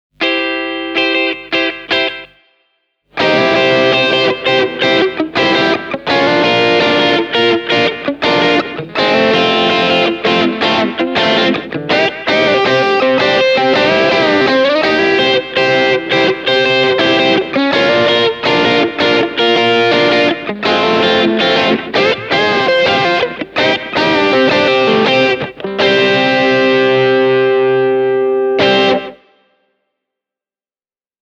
JAM Tube Dreamer 58 säröön (185 €) on saatu mukaan tuhdin annoksen vanhasta Tube Screamerista tuttua kermaista keskialuetta. TD58:ssa on kuitenkin parannettu roimasti pedaalin dynamiikka ja särön putkivahvistin-tyylistä soundia käyttämällä kolmea diodia, jotka tuottavat harmonisesti rikkaan asymmetrisen klippauksen.
Tämä ääninäyte – kuten tämän jutun muutkin pätkät – on äänitetty Fender Stratocasterilla ja Blackstar HT-1R -putkikombolla: